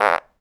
fart_squirt_07.wav